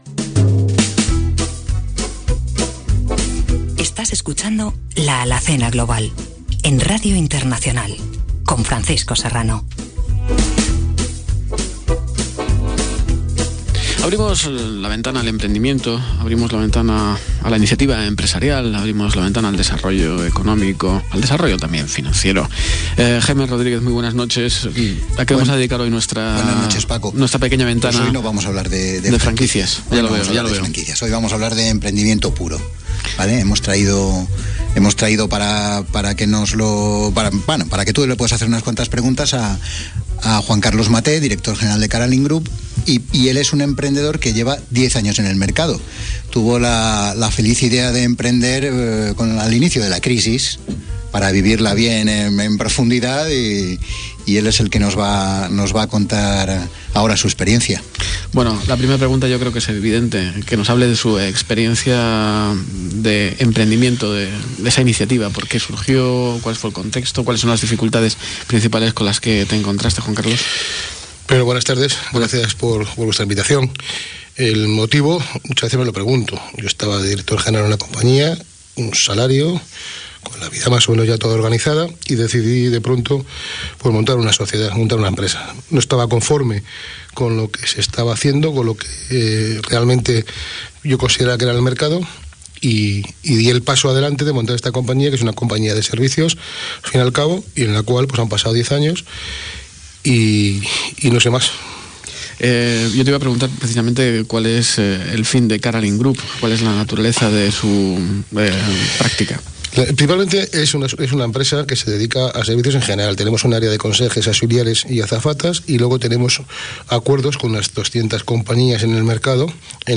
La Alacena Global, el programa de Radio Internacional · Caralin Group